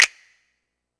vybeSnap.wav